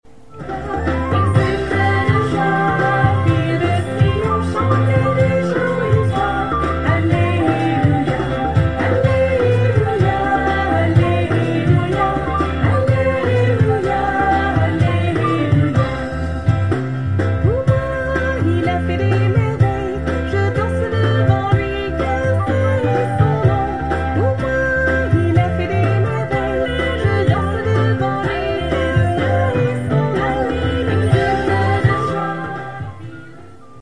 Korg / Guitare / Chant / Flute